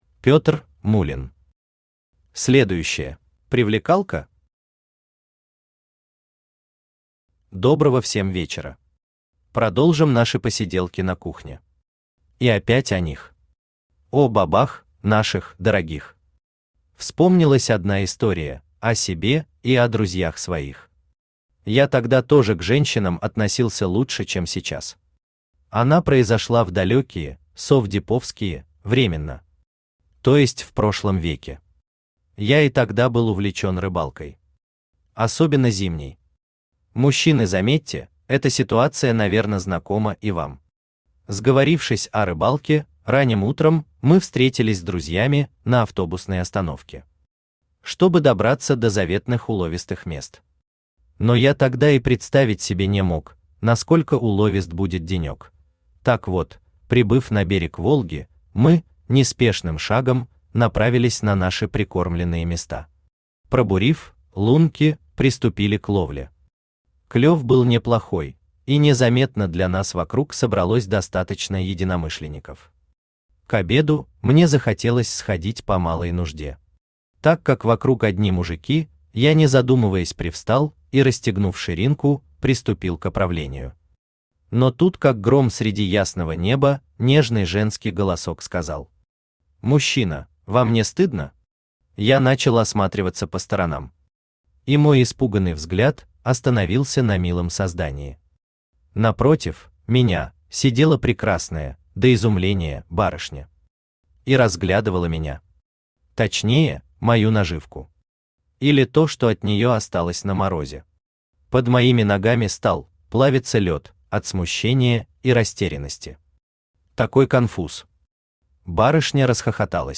Аудиокнига Следующая | Библиотека аудиокниг
Aудиокнига Следующая Автор Пётр Гамильтонович Муллин Читает аудиокнигу Авточтец ЛитРес.